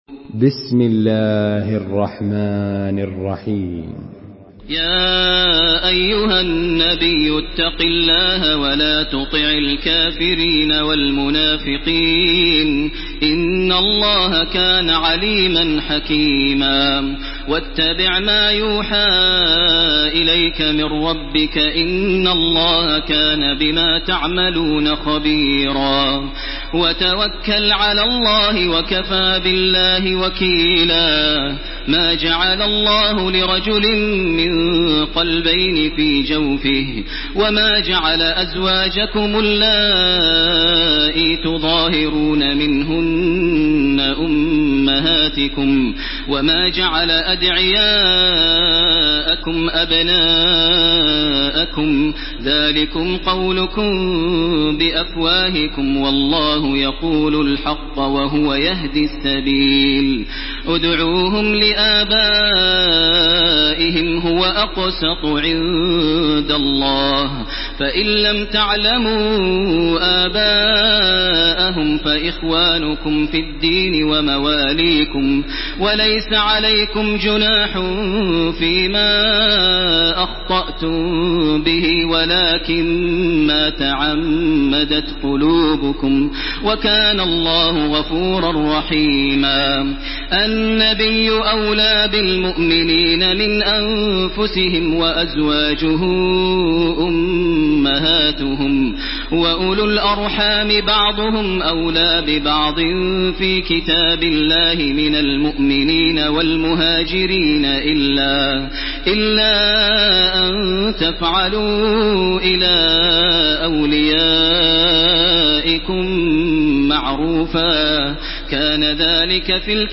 Surah Al-Ahzab MP3 in the Voice of Makkah Taraweeh 1429 in Hafs Narration
Murattal